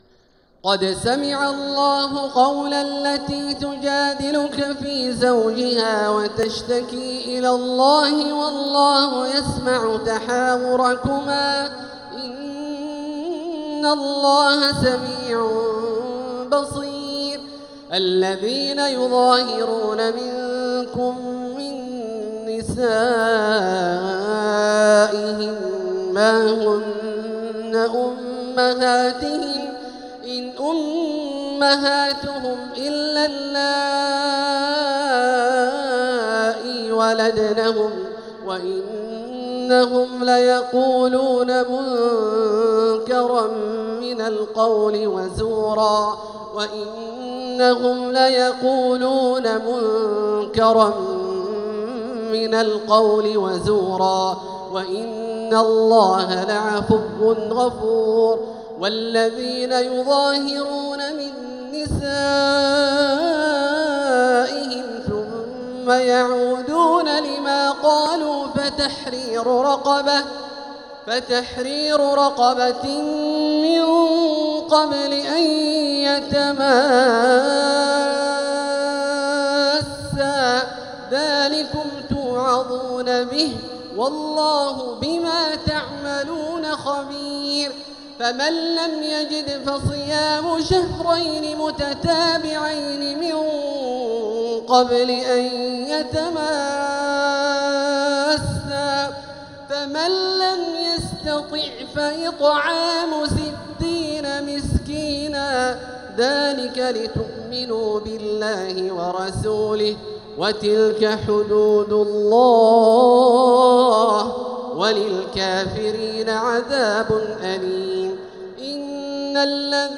سورة المجادلة | مصحف تراويح الحرم المكي عام 1446هـ > مصحف تراويح الحرم المكي عام 1446هـ > المصحف - تلاوات الحرمين